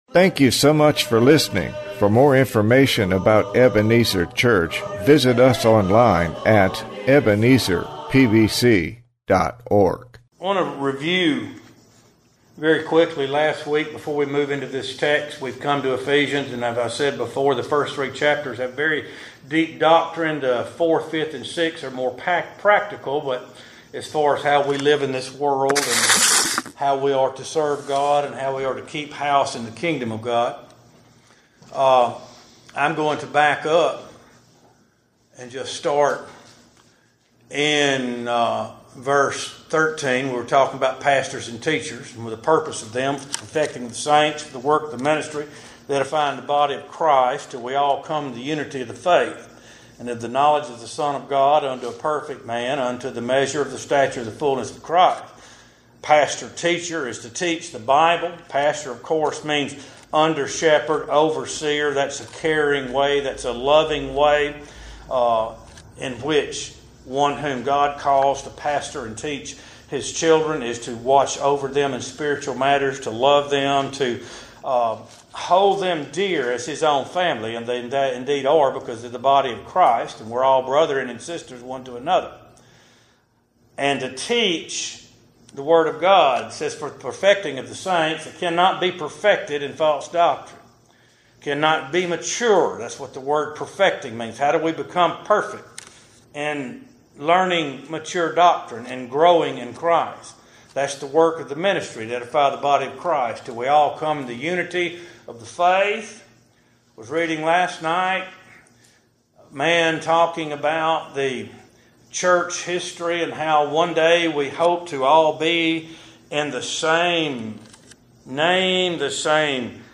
Exposition of Ephesians 4:12-24